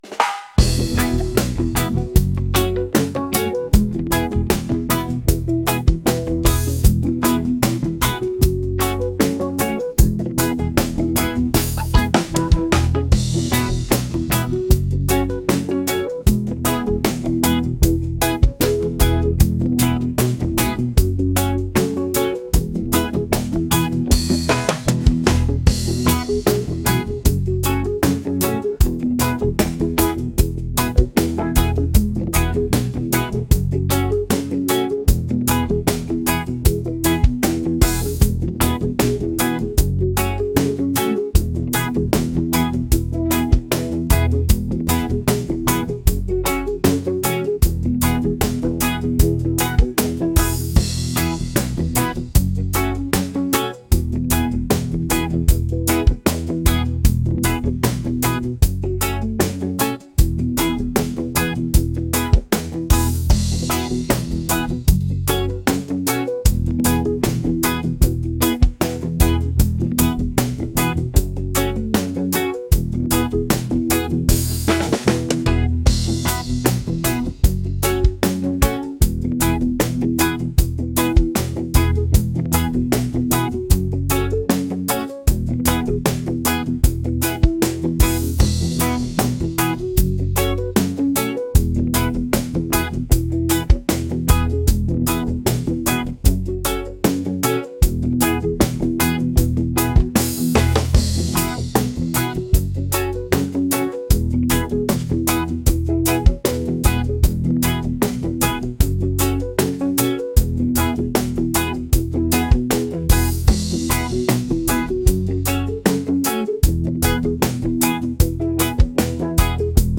upbeat | reggae | island | fusion